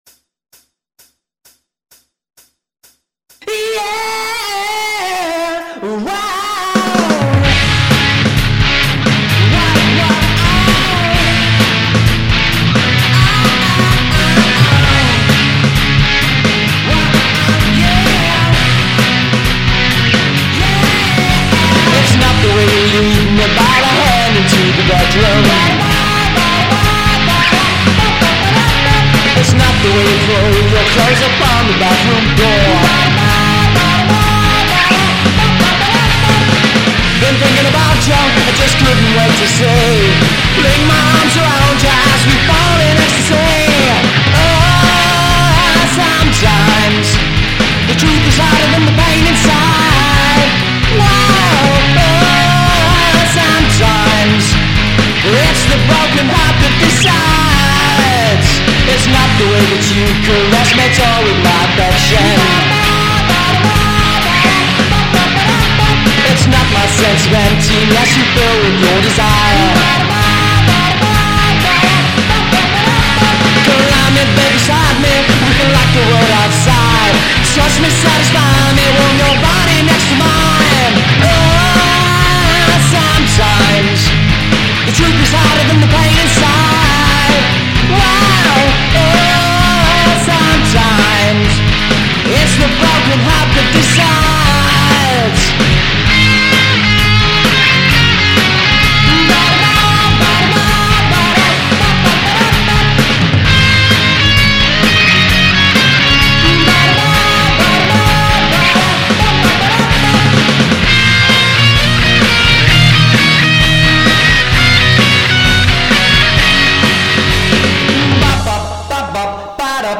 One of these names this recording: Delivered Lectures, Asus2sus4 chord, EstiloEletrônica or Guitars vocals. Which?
Guitars vocals